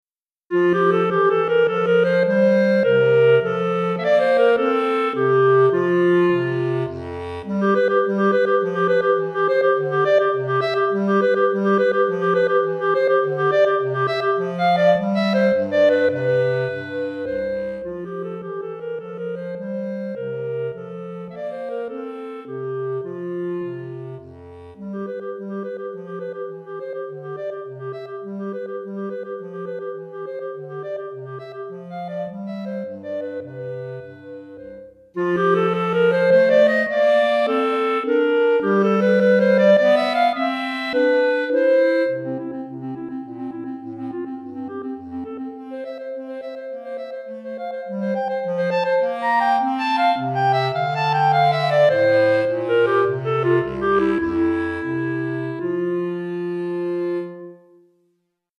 Clarinette en Sib et Clarinette Basse